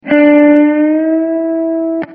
Tyle, że ciągnąć będziemy strunę znacznie dalej.